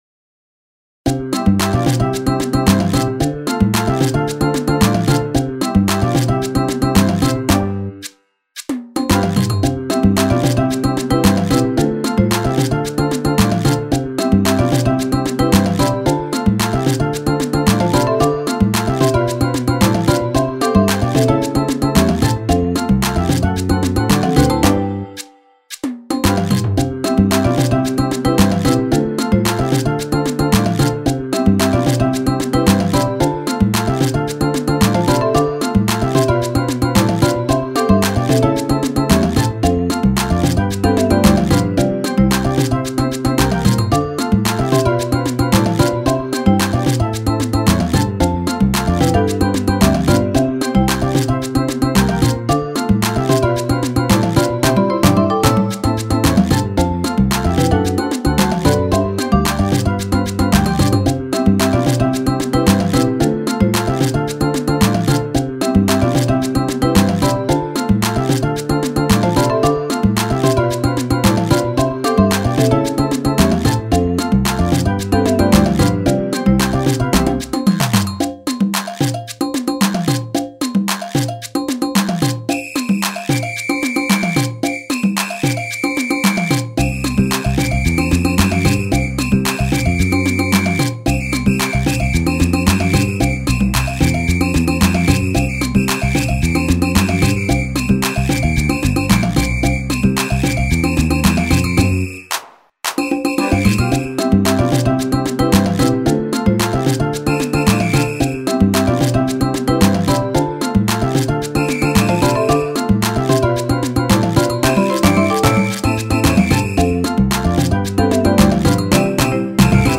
BGM
ロング明るい穏やか